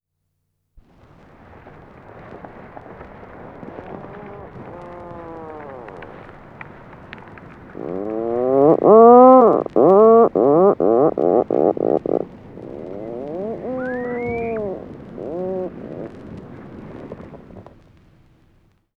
Puffin (auch Papageitaucher) Ruf
Puffin-Geraeusche-Voegel-in-Europa.wav